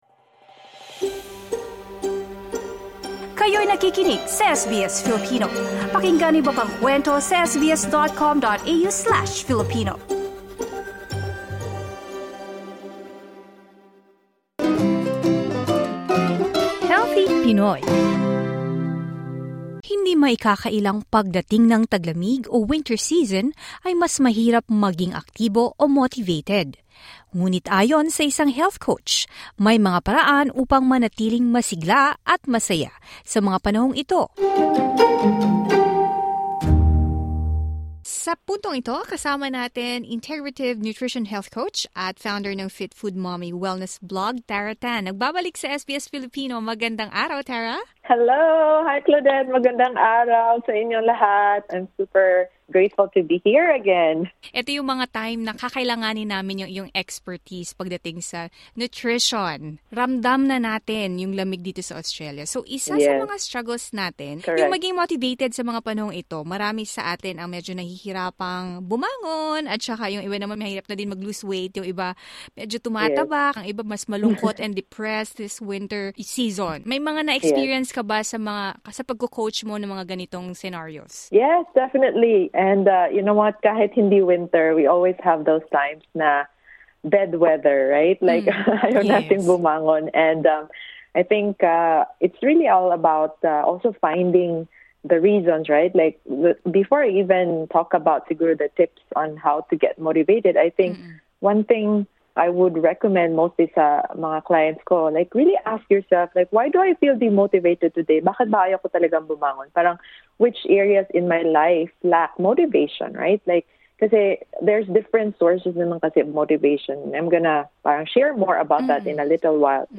In an interview with SBS Filipino , she shares some habits and routines you can incorporate in your daily life to help beat the winter blues.